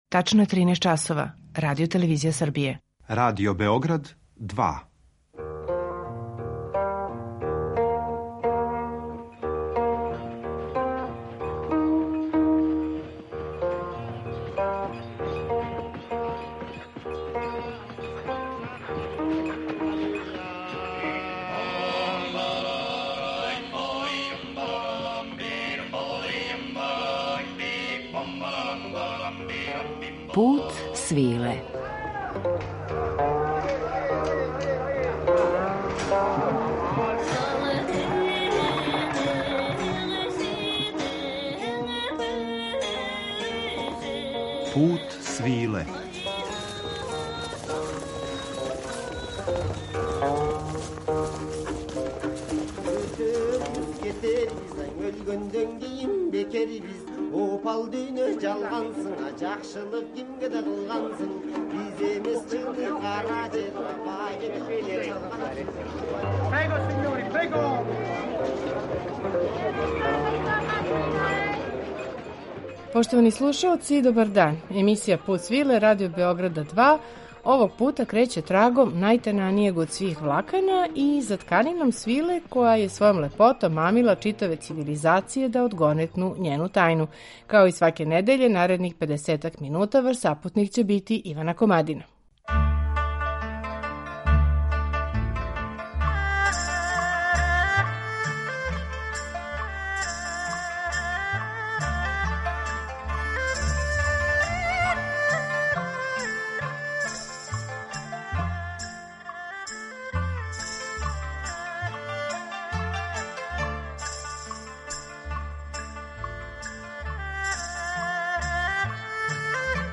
U današnjem Putu svile bavimo se istorijom izrade svilenih niti, kao i mitovima vezanim uz njih, uz muziku iz zemalja u kojima su svilene tkanine najranije počele da se izrađuju: Kine, Indije, Japana, Nepala, Persije...